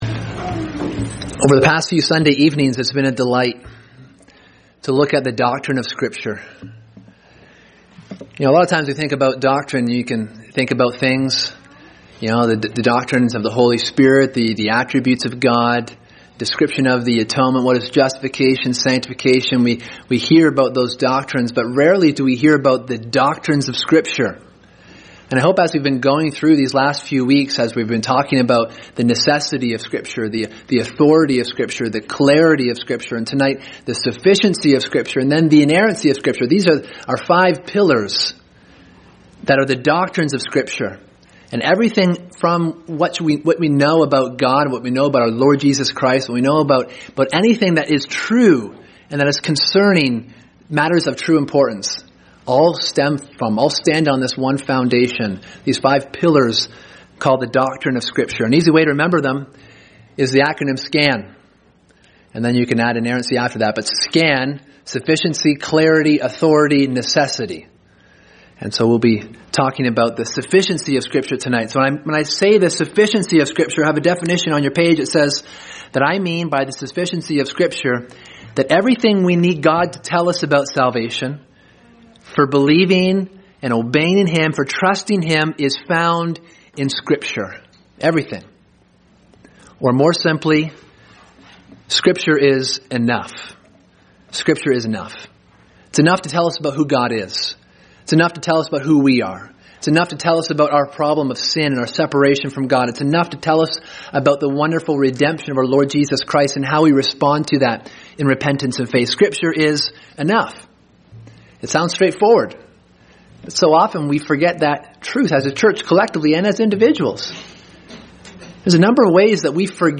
Sermon: The Sufficiency of Scripture